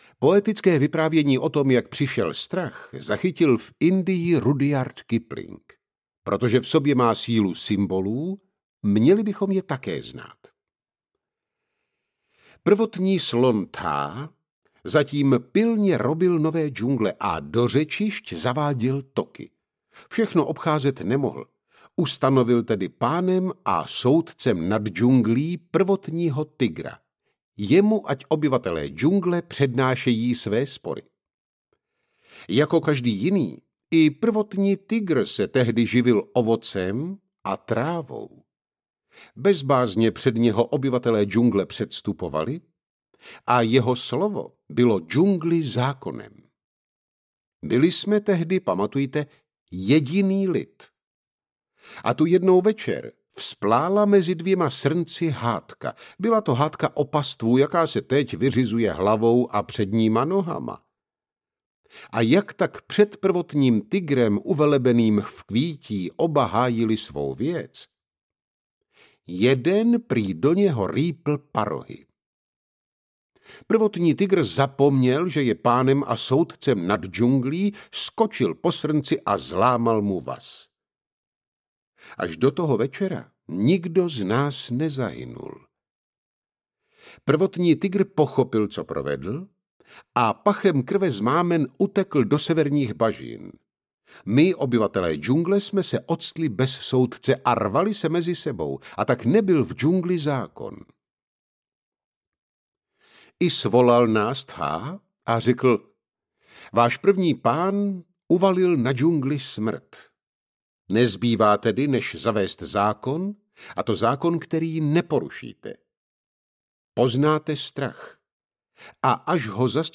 audiokniha
Čte: Zbyšek Horák